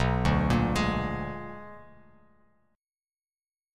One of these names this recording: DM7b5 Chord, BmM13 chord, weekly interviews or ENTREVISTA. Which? BmM13 chord